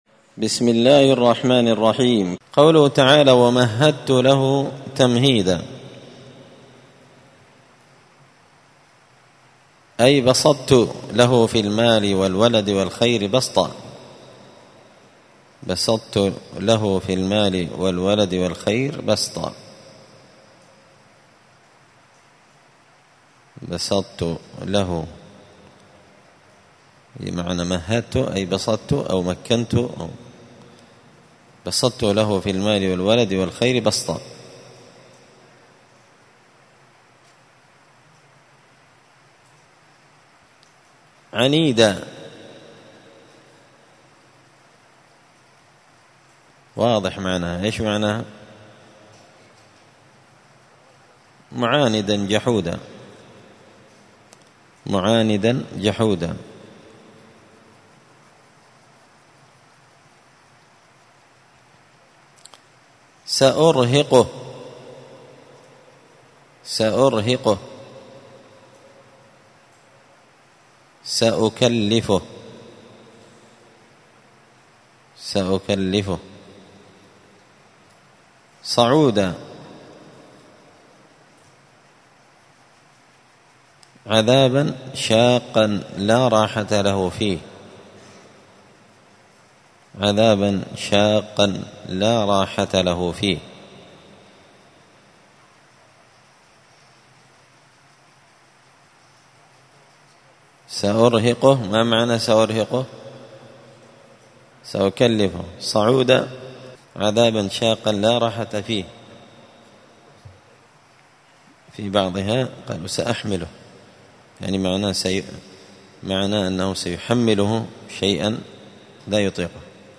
(جزء تبارك سورة المدثر الدرس 96)